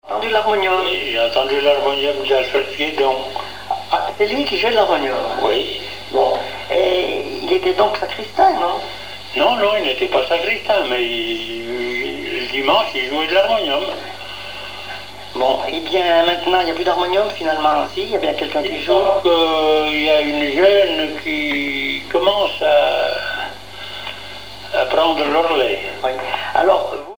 L'harmonium
Son de l'instrument
Pièce musicale inédite